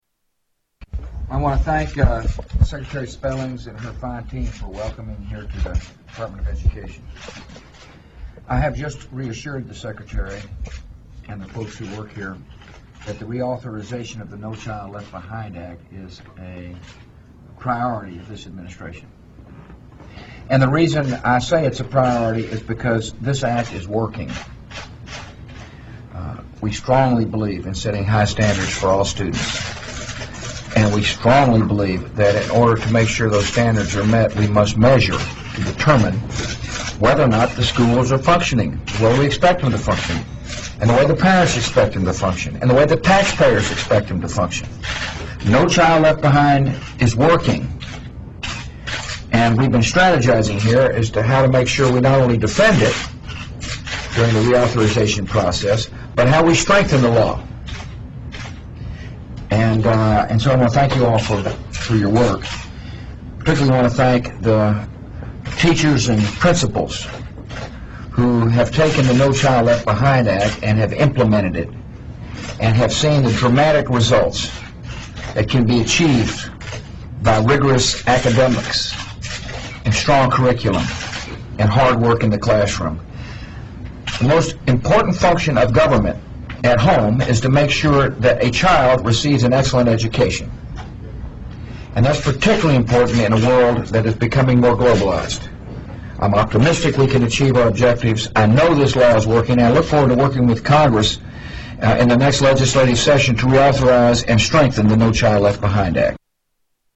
Tags: Historical Top 11-20 Censored News Stories 2009 Censored News Media News Report